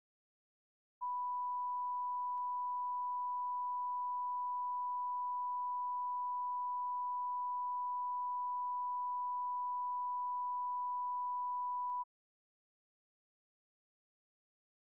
On July 24, 1972, President Richard M. Nixon and H. R. ("Bob") Haldeman talked on the telephone from 12:45 pm to 12:46 pm. The White House Telephone taping system captured this recording, which is known as Conversation 027-045 of the White House Tapes.
[Unintelligible]